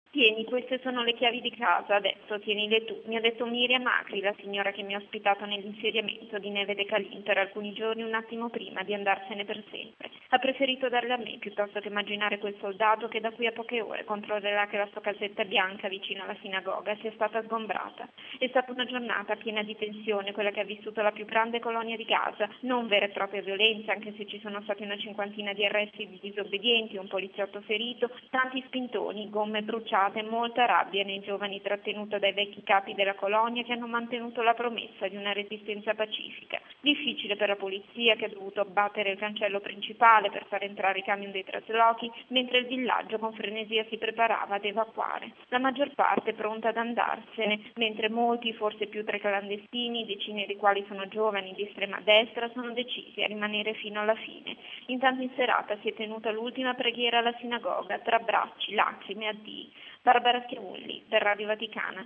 E proprio in mattinata è in corso l’evacuazione forzata: dall’alba di oggi centinaia di soldati, scortati da bulldozer, stanno sfidando la resistenza di chi non vuole partire. Il reportage